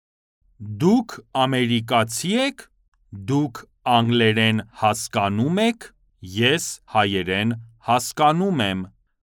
Male
Adult